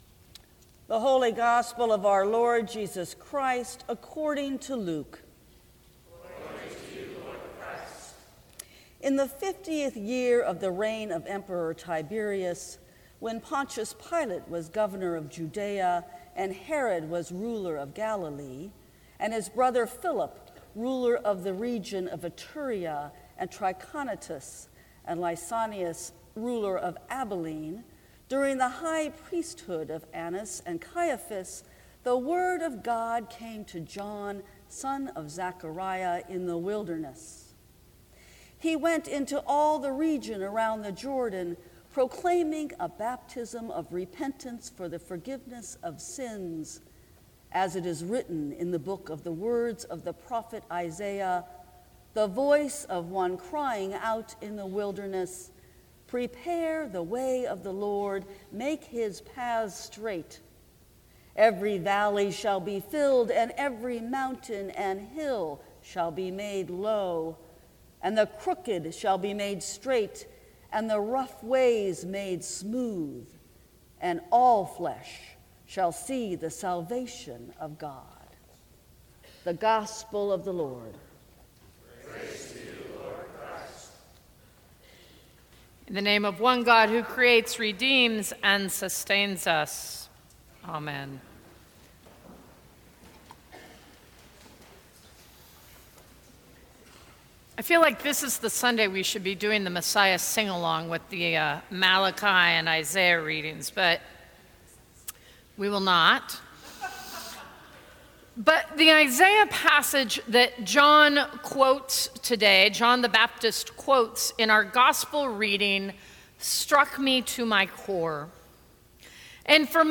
Sermons from St. Cross Episcopal Church Prepare Your Heart Dec 13 2018 | 00:17:06 1x 00:00 / 00:17:06 Subscribe Share Apple Podcasts Spotify Overcast RSS Feed Share Link Embed